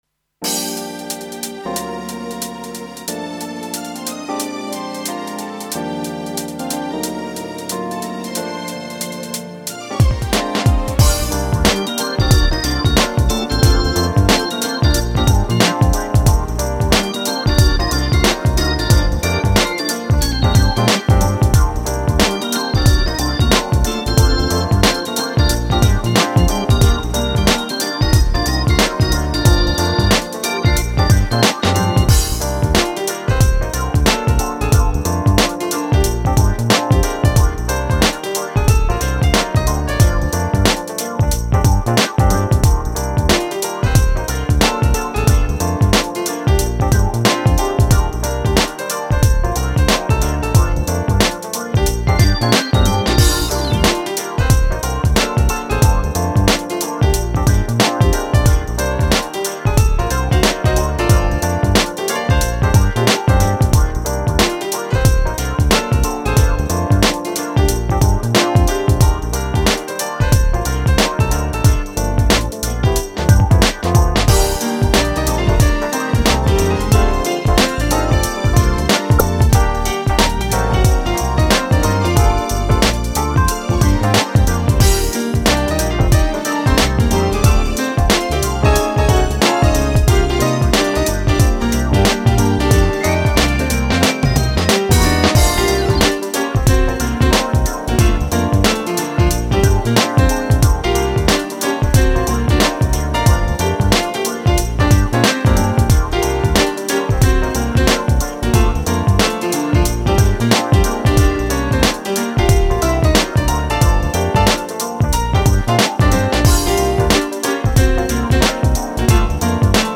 2007-03-02 비트감 위에 펼쳐지는 피아노가 상콤하군요 댓글 쓰기 댓글 쓰기 목록 보기